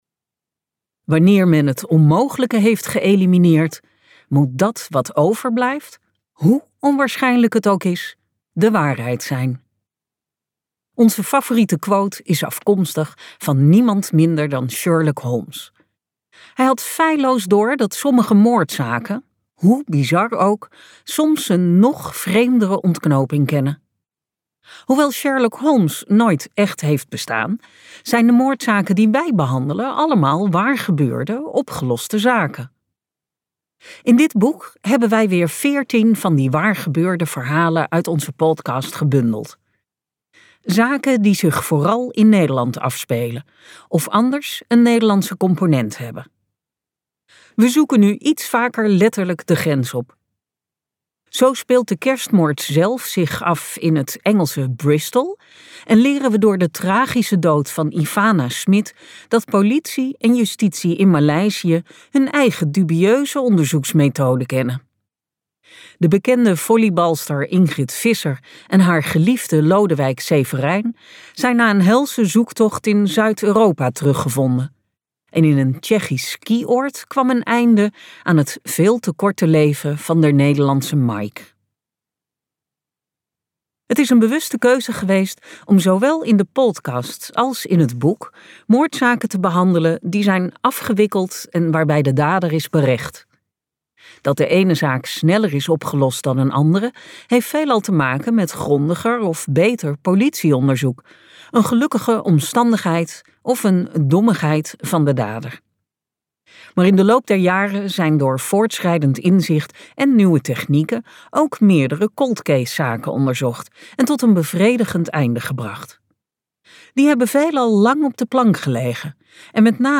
Ambo|Anthos uitgevers - De kerstmoord luisterboek